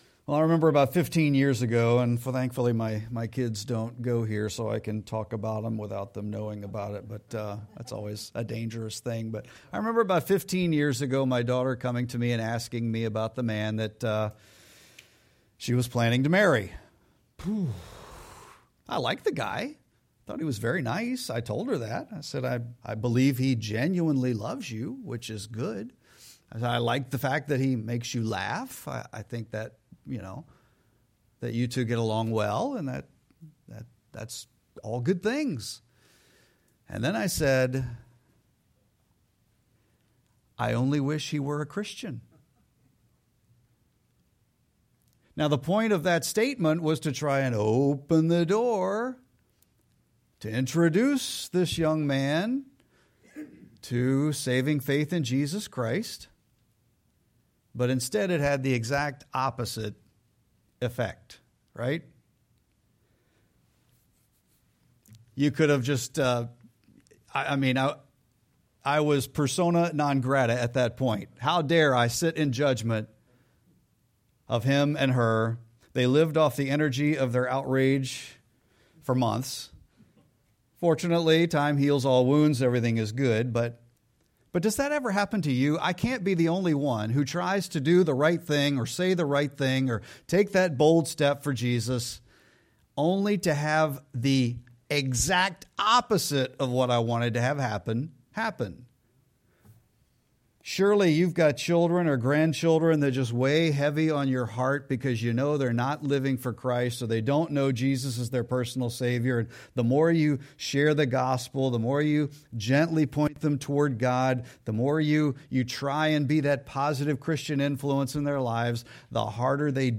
Sermon-3-15-26.mp3